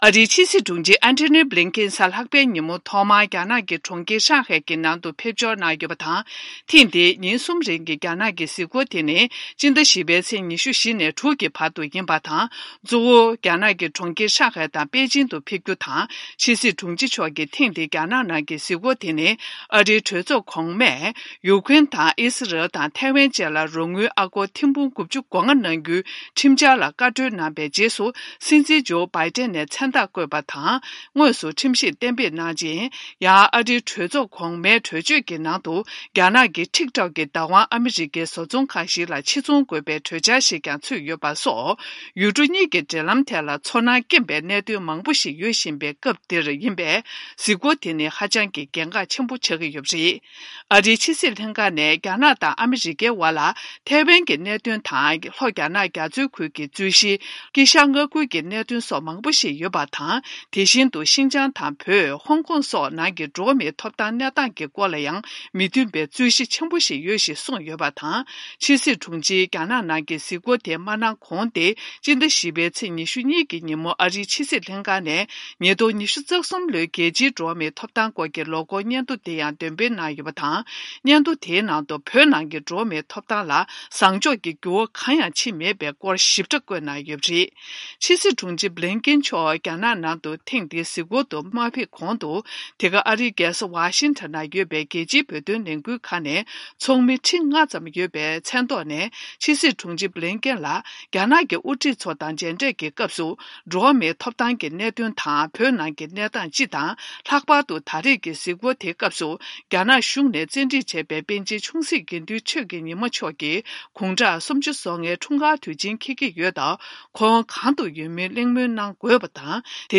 སྙན་སྒྲོན་ཞུ་ཡི་རེད།